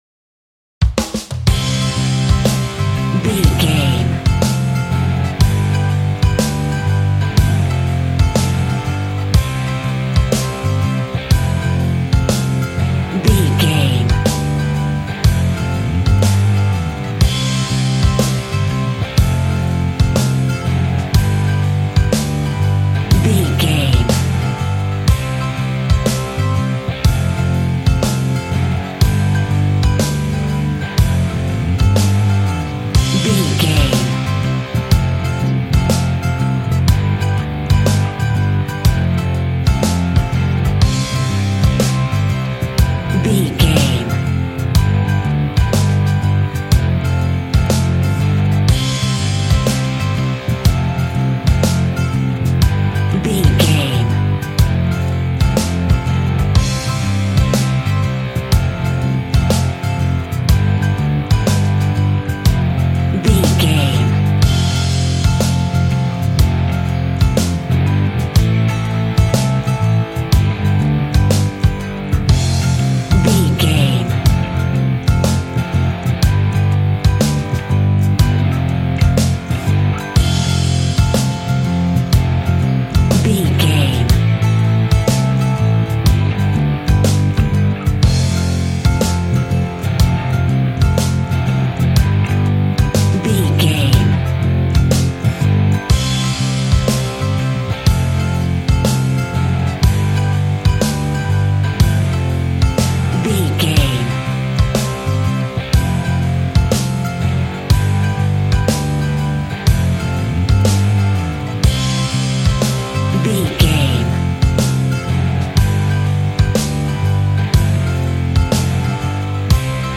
Ionian/Major
groovy
happy
electric guitar
bass guitar
drums
piano
organ